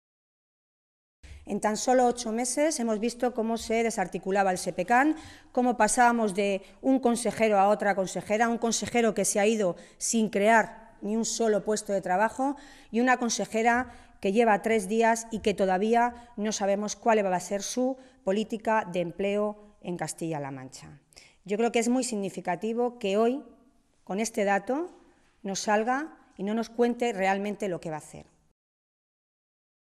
Milagros Tolón, diputada regional del PSOE de Castilla-La Mancha
Cortes de audio de la rueda de prensa